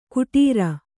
♪ kuṭīra